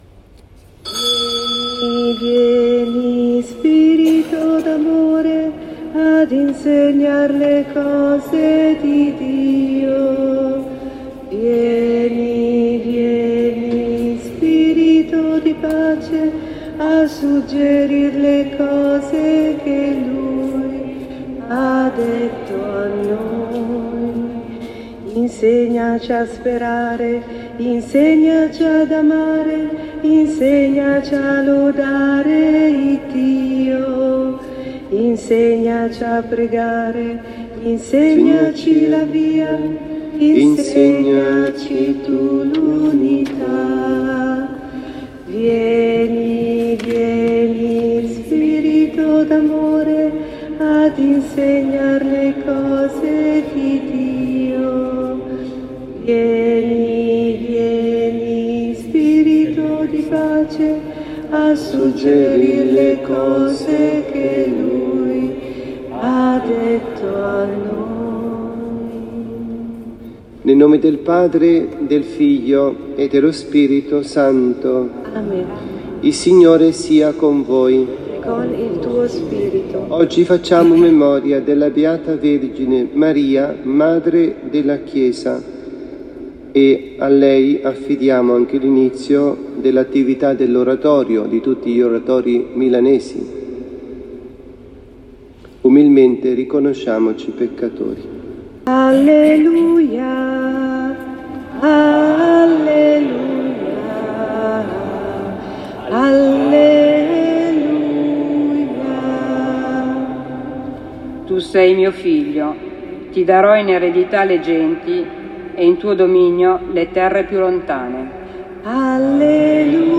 Omelia
dalla Parrocchia S. Rita – Milano